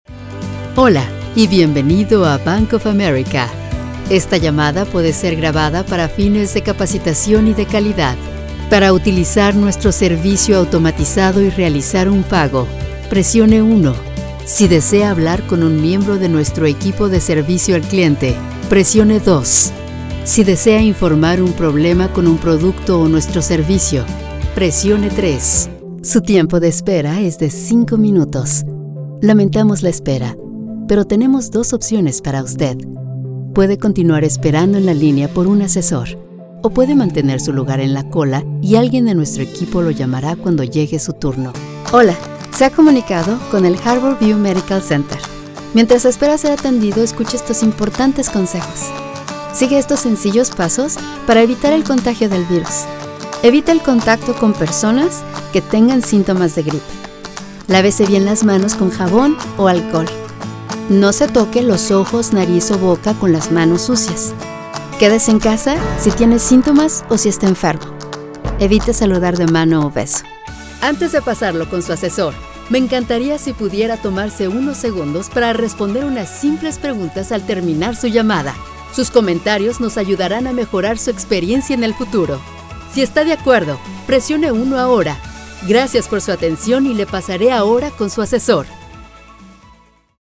Adult, Young Adult
Has Own Studio
Spanish - Latin American
commercial